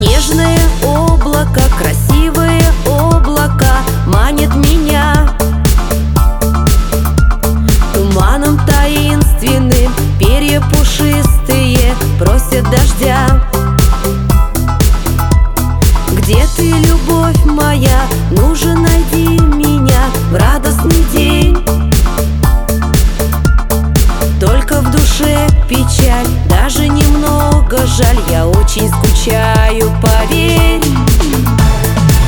• Качество: 320, Stereo
русский шансон